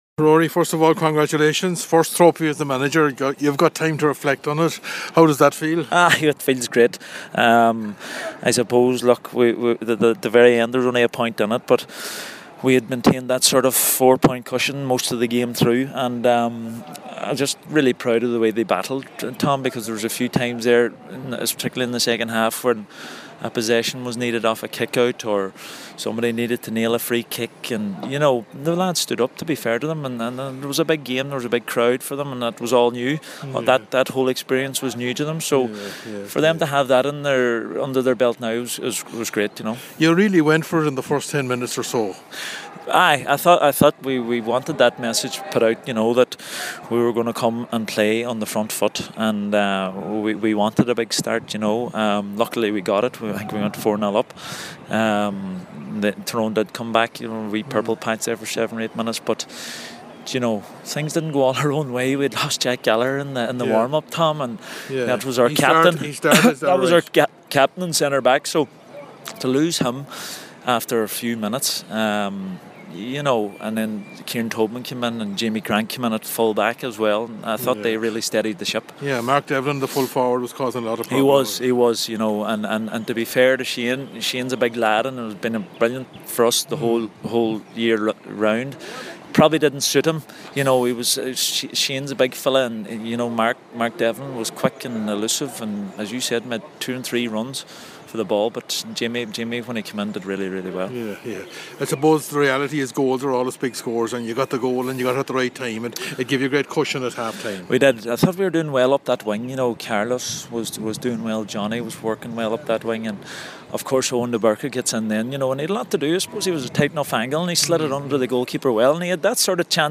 After the game